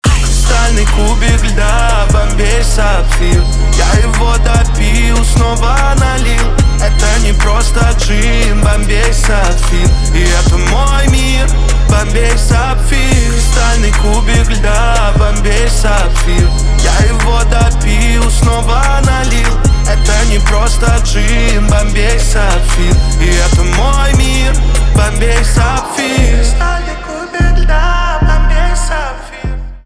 русский рэп
басы , качающие